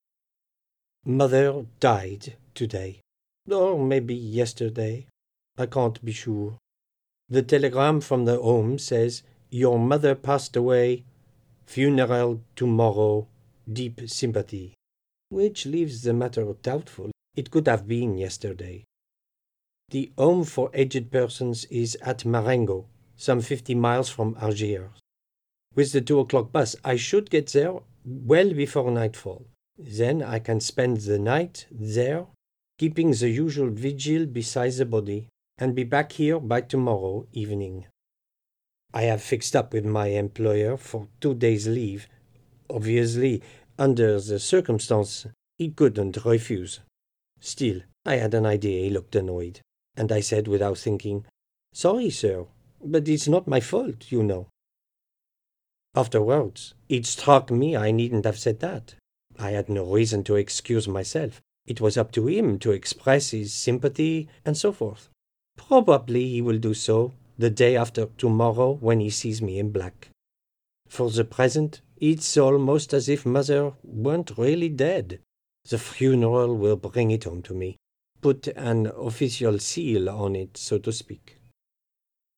Regional and Foreign Accents
English with French Accent - Camus